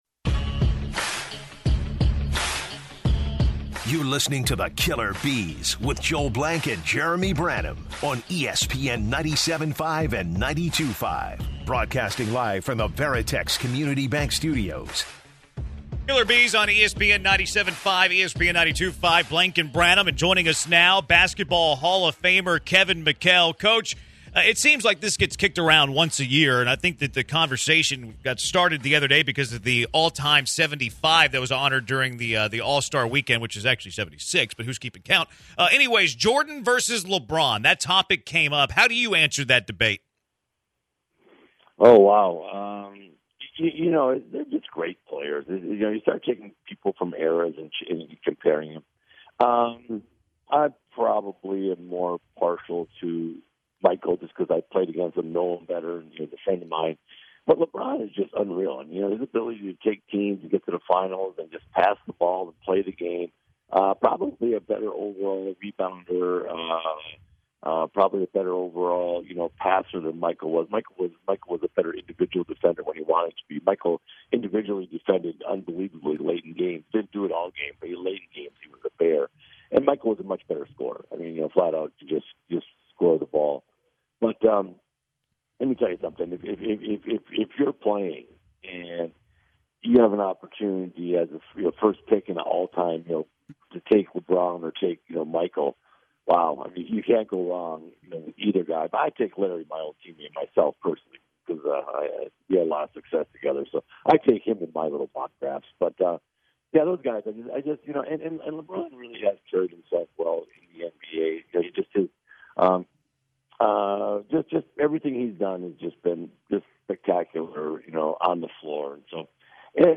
He also talks about the recent Kemba Walker news of him sitting the rest of the season and what does he think about this trend happening in the NBA. To wrap up the interview McHale gives his input on the Juwan Howard altercation he had with the Maryland coaching staff.